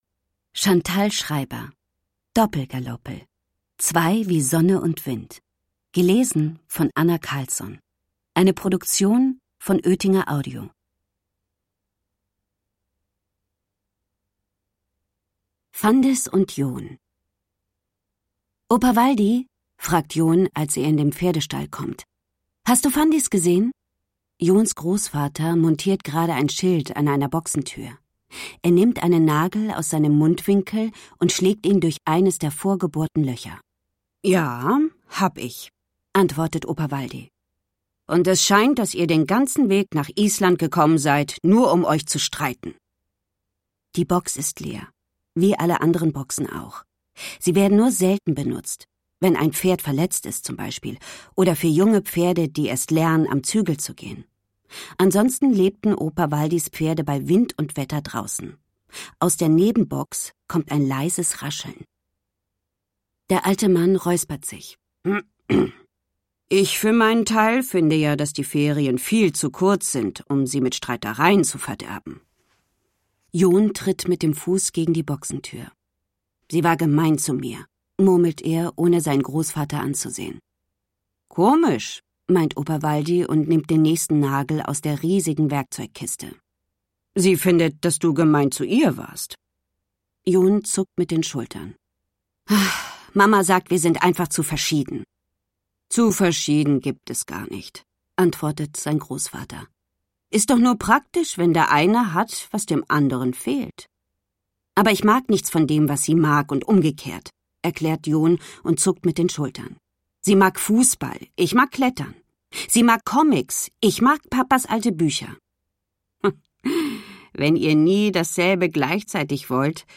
Hörbuch: Doppel-Galoppel 1.